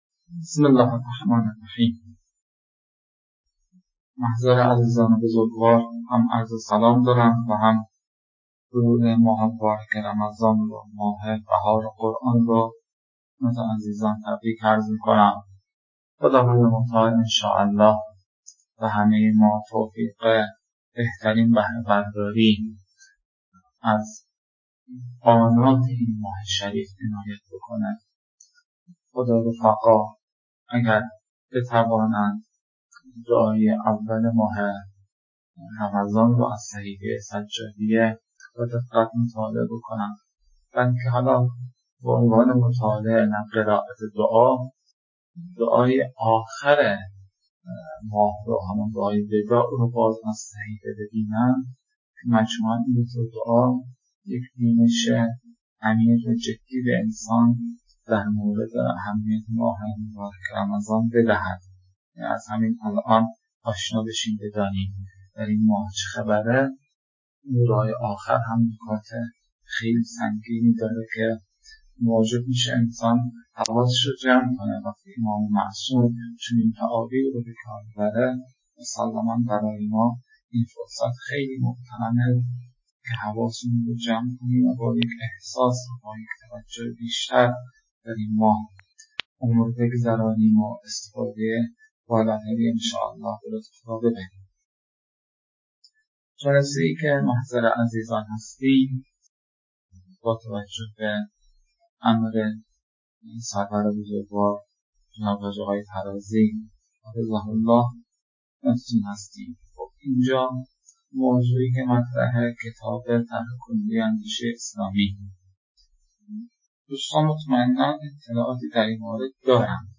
🔸 لازم به‌ذکر است که نتیجه این رویکرد، گرفته شدن وقت قابل توجهی در محتوای صوت‌ها به رفت‌وبرگشت بین استاد و طلاب است که در کنار مجازی برگزارشدن کلاس، حوصله خاصی را در گوش دادن می‌طلبد.